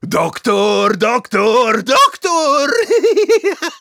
Voice file from Team Fortress 2 German version.
Spy_dominationmedic02_de.wav